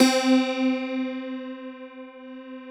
53q-pno10-C2.aif